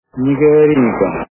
» Звуки » Из фильмов и телепередач » Белое солнце пустыни - Не говори никому